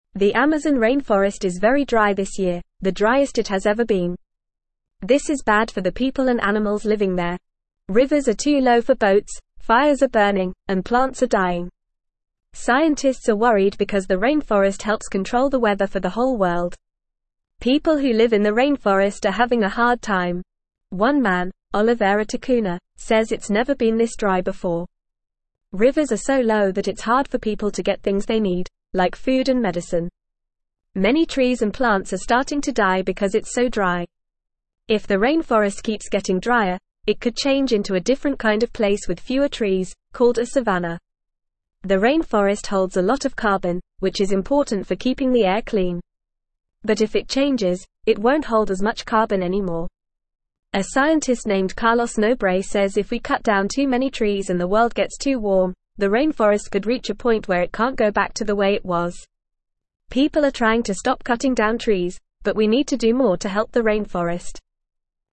Fast
English-Newsroom-Lower-Intermediate-FAST-Reading-Amazon-Forest-in-Trouble-Drought-Fires-and-Concerns.mp3